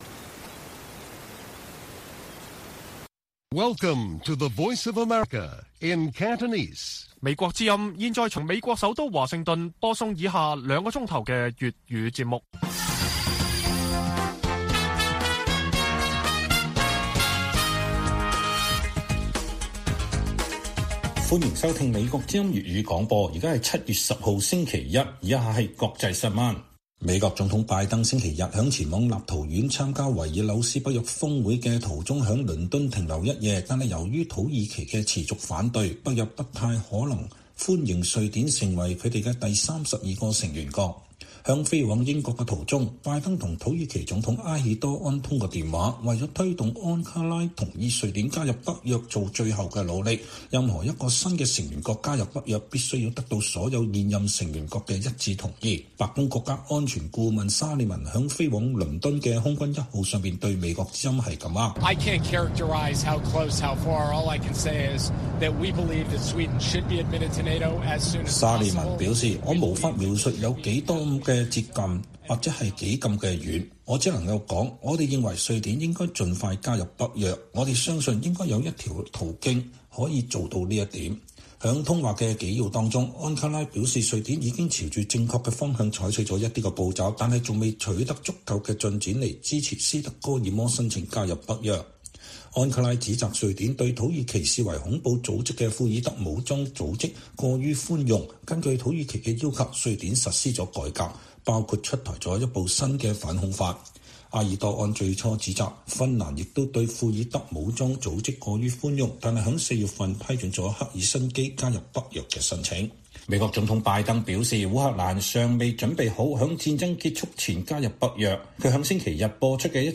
粵語新聞 晚上9-10點: 709事件八週年，北京繼續迫害人權律師